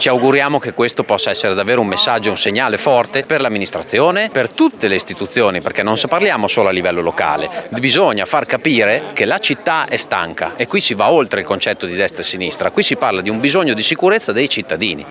In 300 per chiedere sicurezza in zona tempio-stazione. Le interviste
Successo per la manifestazione in zona tempio stazione organizzata oggi dal comitato Modena Merita di più.